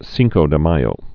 (sēngkō dəyō)